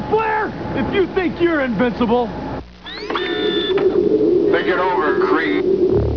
Here’s a clip from one of the commercials…